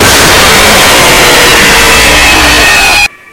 Fazbear Return 4 Jumpscare Sound 3 Sound Effect Download: Instant Soundboard Button